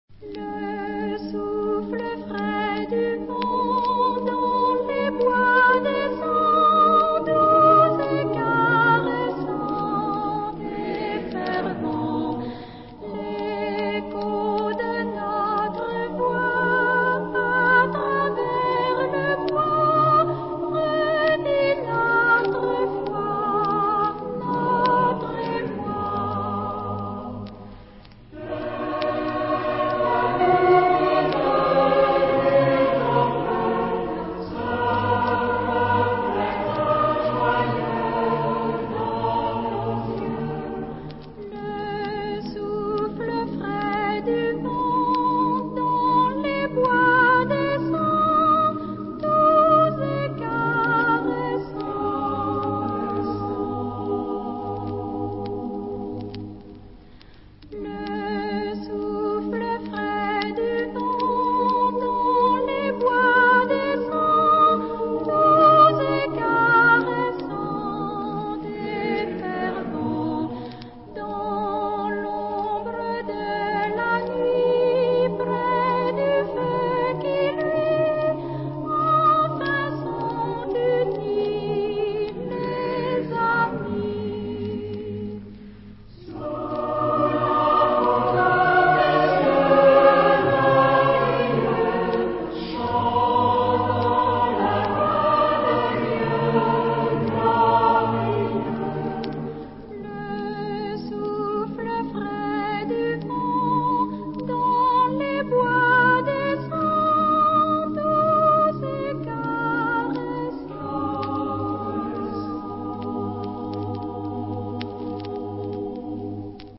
Arm.: Geoffray, César (1901-1972) [ Francia ]
Género/Estilo/Forma: Profano ; Tradicional ; Canción
Tipo de formación coral: SATB  (4 voces Coro mixto )
Tonalidad : sol menor
por La Psalette de Lyon dirigido por César Geoffray